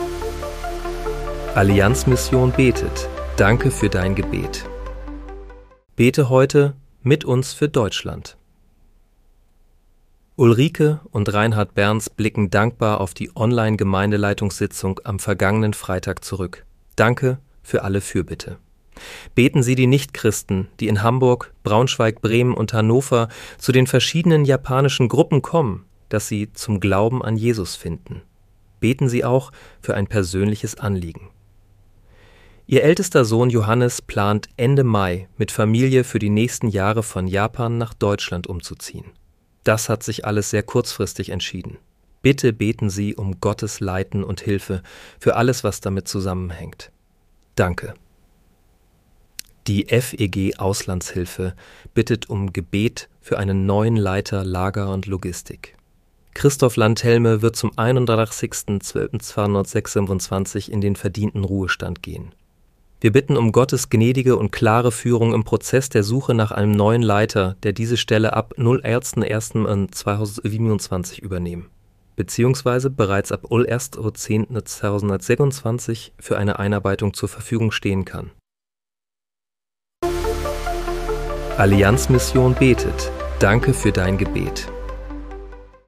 (KI-generiert mit